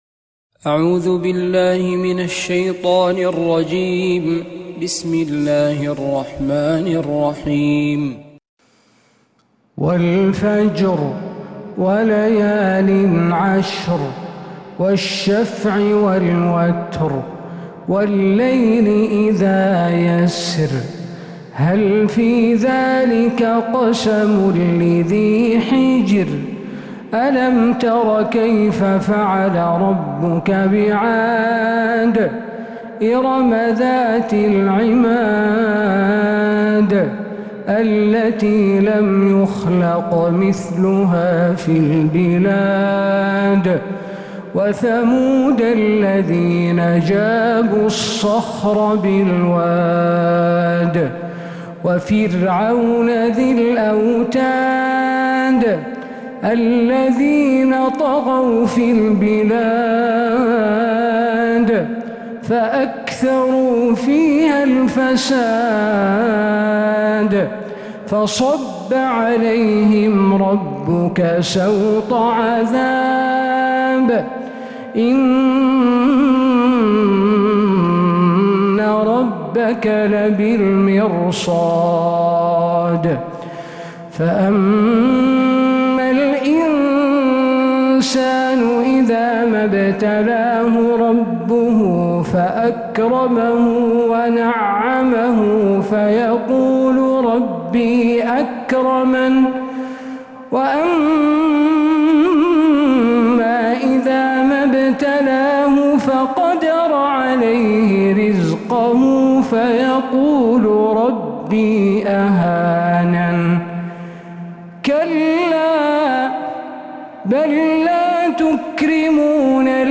سورة الفجر كاملة من مغربيات الحرم النبوي